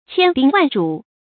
千叮萬囑 注音： ㄑㄧㄢ ㄉㄧㄥ ㄨㄢˋ ㄓㄨˇ 讀音讀法： 意思解釋： 叮：叮嚀。反復叮囑；再三吩咐。